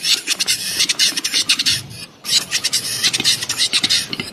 Toki, czyli okres godowy rozpoczęły głuszce z Nadleśnictwa Głęboki Bród na Sejneńszczyźnie. Charakterystycznym sygnałem jest pieśń godowa głuszców, którą można teraz usłyszeć w lasach Sejneńszczyzny.
gluszce.mp3